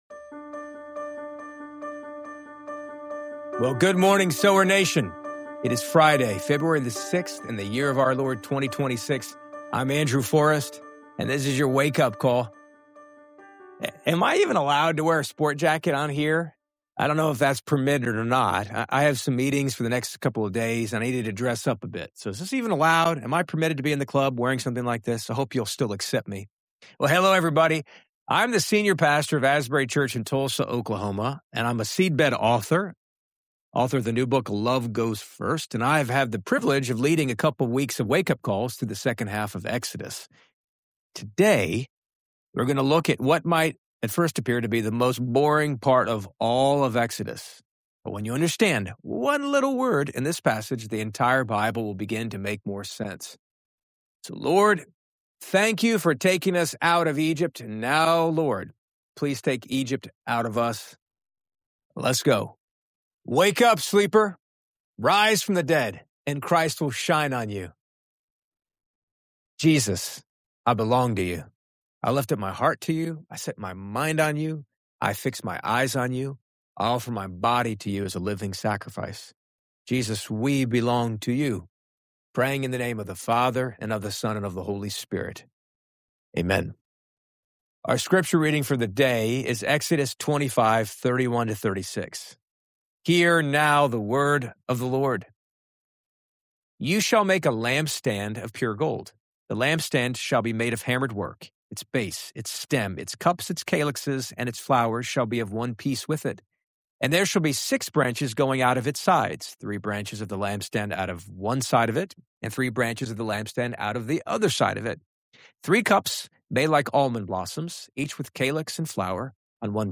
Stay tuned to the end for a moving hymn and three thought-provoking journal prompts to guide your own spiritual journey.